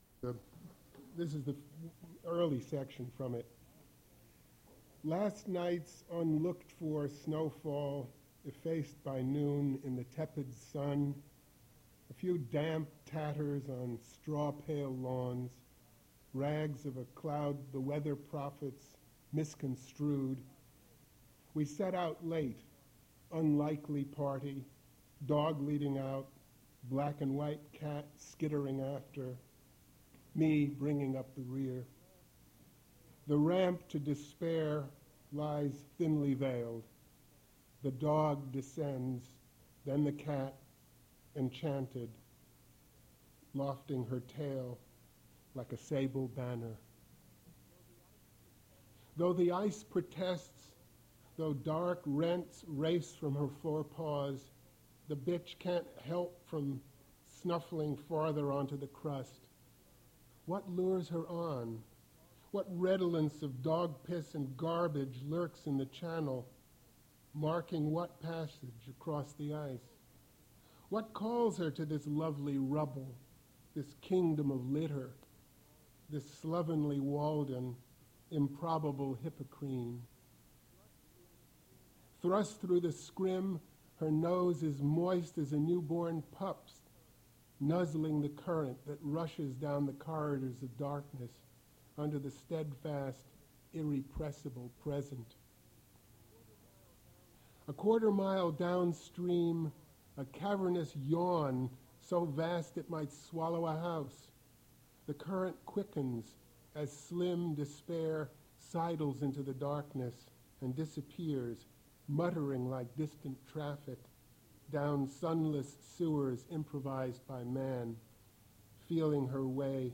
Poetry reading featuring Donald Finkel
Attributes Attribute Name Values Description Donald Finkel reading his poetry at Duff's Restaurant.
mp3 edited access file was created from unedited access file which was sourced from preservation WAV file that was generated from original audio cassette.
recording starts at the poet's performance